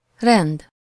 Ääntäminen
IPA: [ɔʁdʁ]